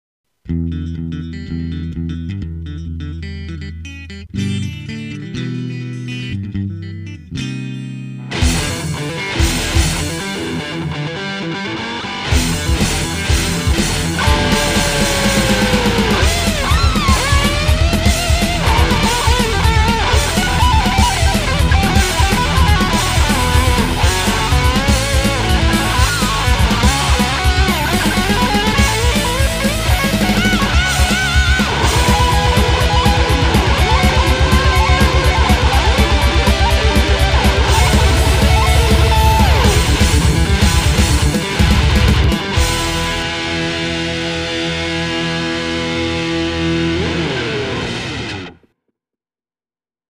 guitar instrumental
このページの曲はすべてコピー（またはｶｳﾞｧｰ）です。